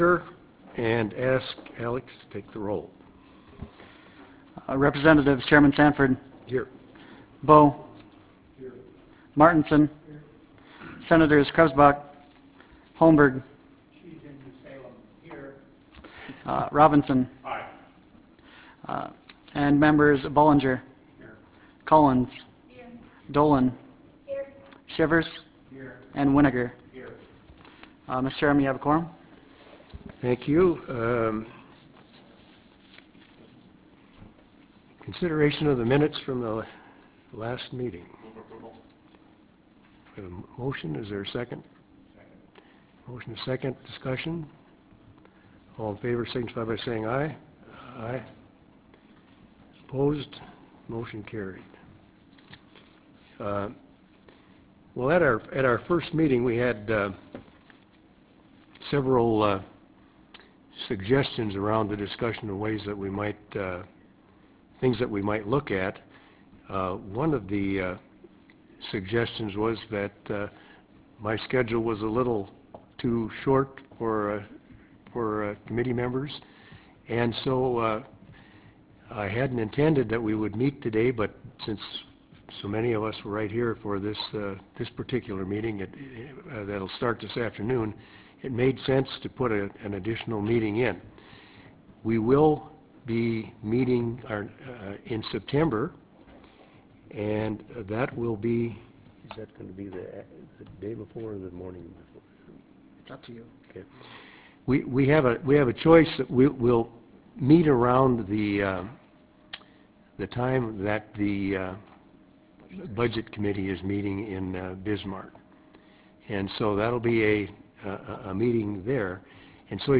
Ballroom, Student Center Dickinson State University Dickinson, ND United States